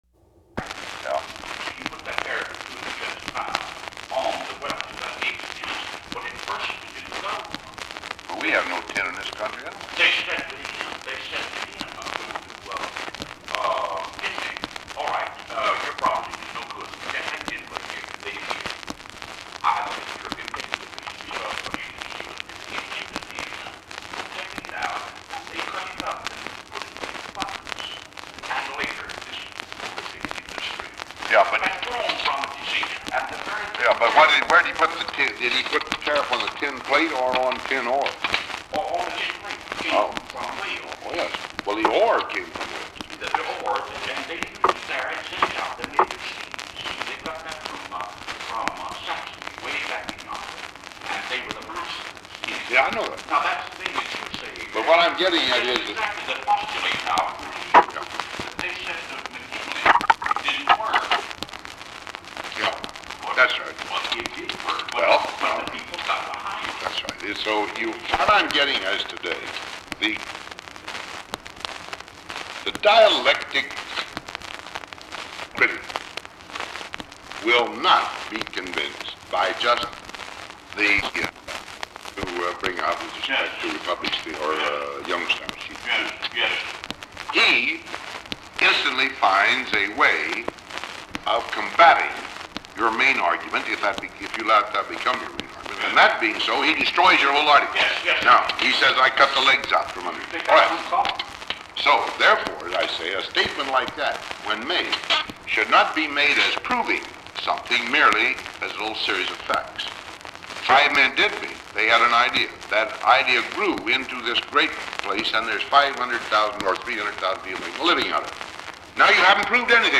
The recording continues the conversation from the previous belt.
Secret White House Tapes | Dwight D. Eisenhower Presidency